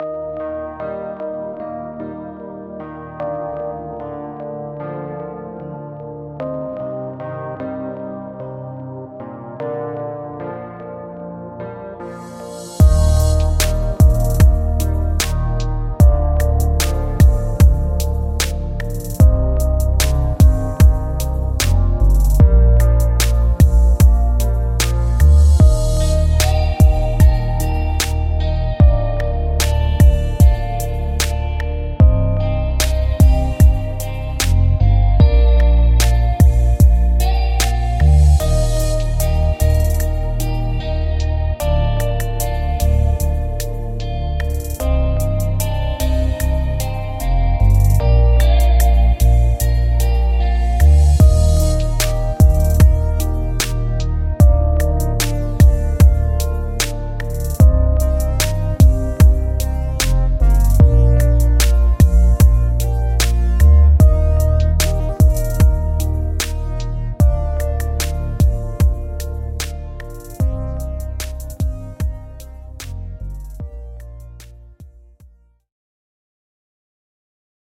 Genre: Lo-Fi